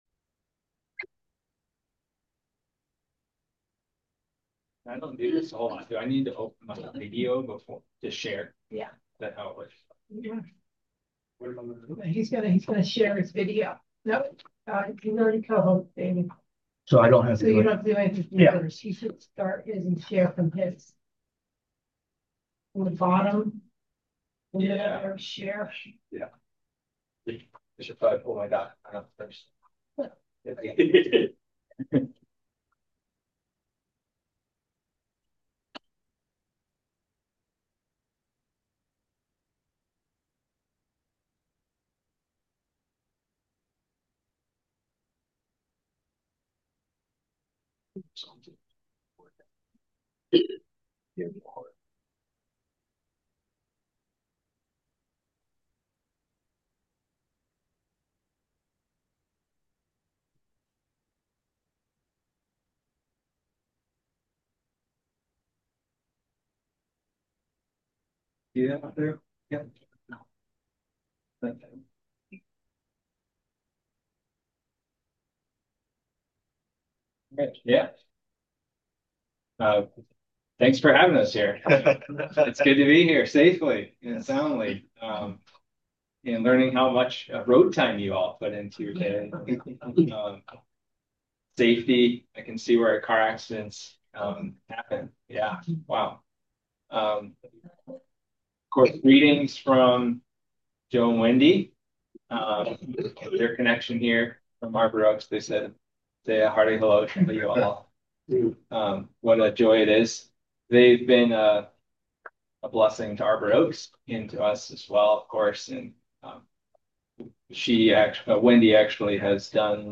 Missionary Report